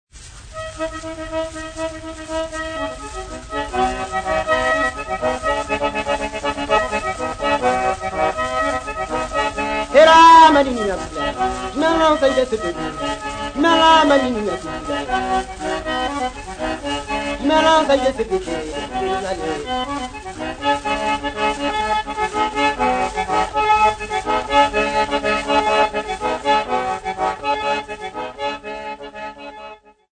Popular music--Africa
Field recordings
Africa Lesotho city not specified f-lo
sound recording-musical
Indigenous music
Vestax BDT-2500 belt drive turntable